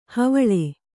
♪ havaḷe